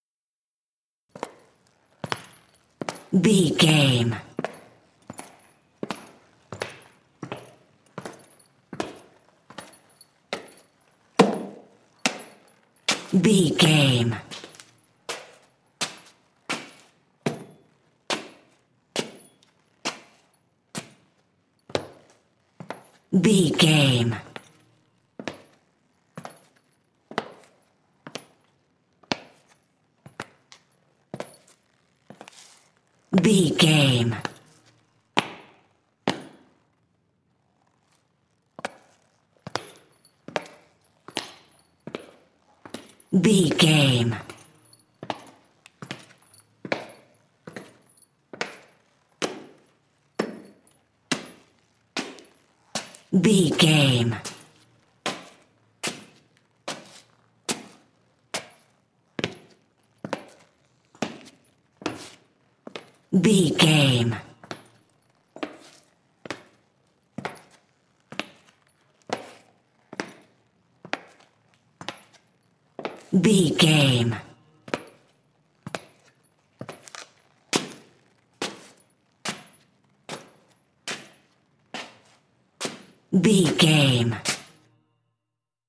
Footsteps cowboy boots 140
Sound Effects
foley